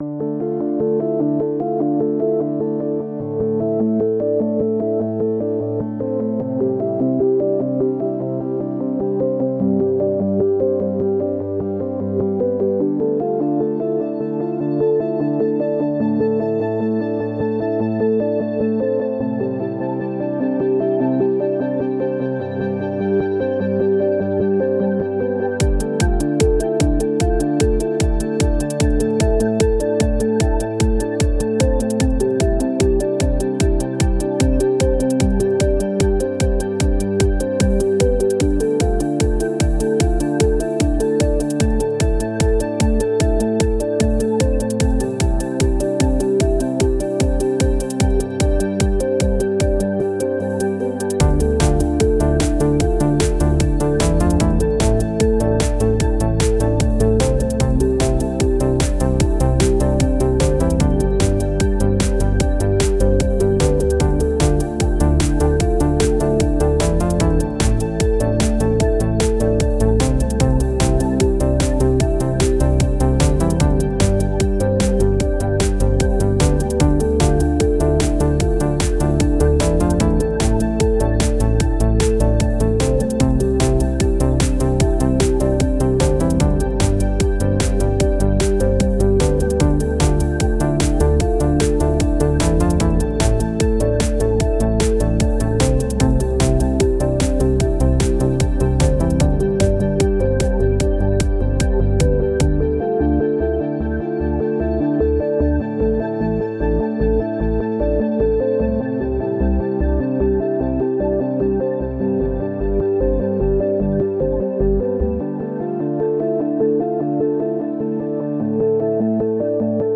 • Music is loop-able, but also has an ending